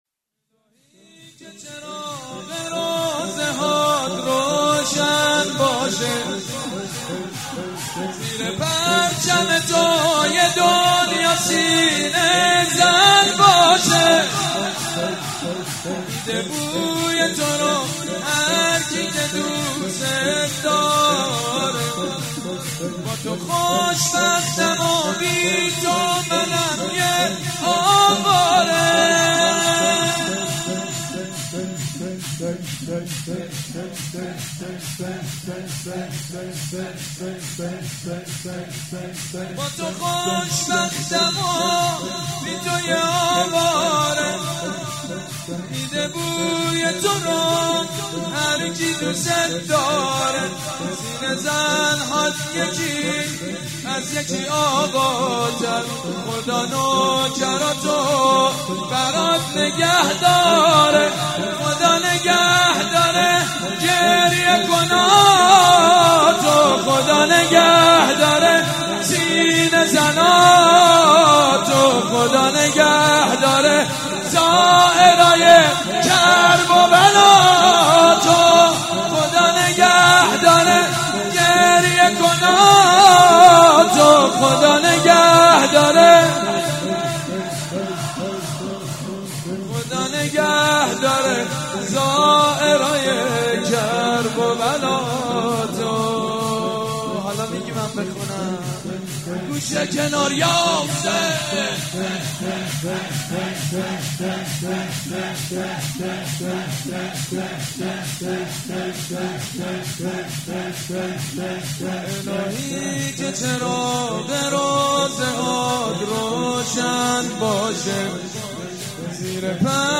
شب چهارم محرم الحرام 1394
الهی که چراغ روضه هات روشن باشه | شور | حضرت امام حسین علیه السلام